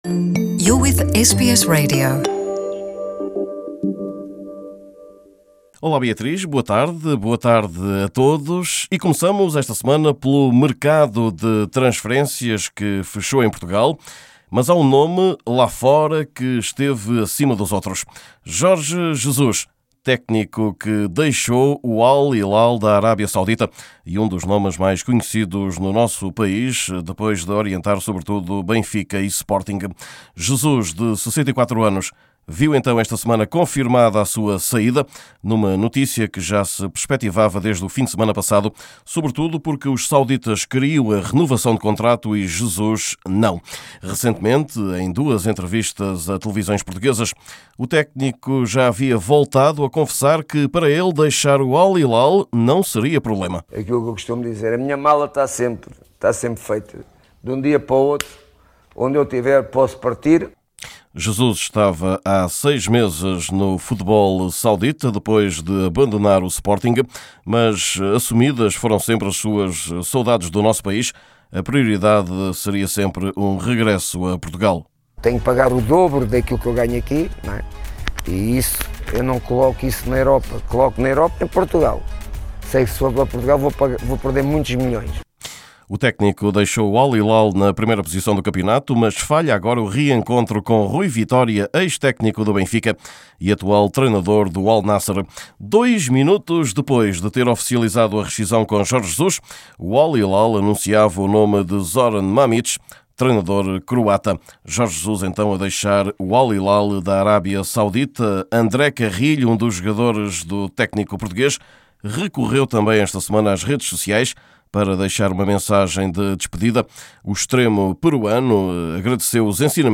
Neste boletim semanal vamos saber ainda o que se passa com um ex-internacional luso, que se diz magoado com o seu antigo clube.